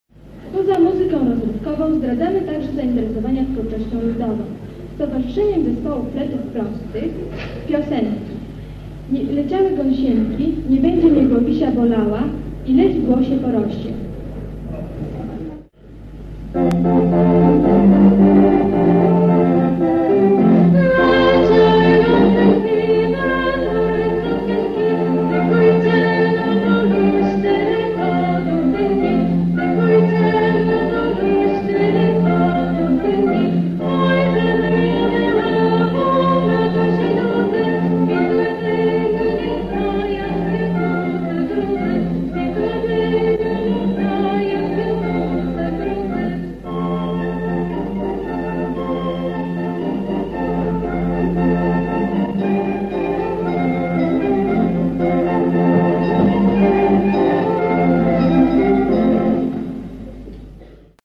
Tylko tyle udało się jak na razie odnaleźć, a ich jakość, delikatnie mówiąc, nie jest najlepsza.
Zapowiedź melodii ludowych i "Leciały gąsieńki"